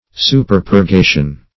Superpurgation \Su`per*pur*ga"tion\, n. Excessive purgation.